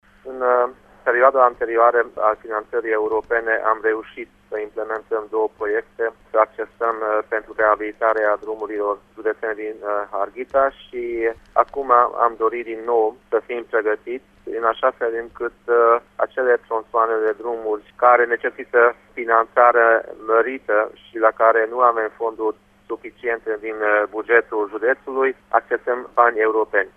Borboly Csaba a mai spus că deja sunt în pregătirre proiectele pentru atragerea de noi fonduri europene pe infrastructură: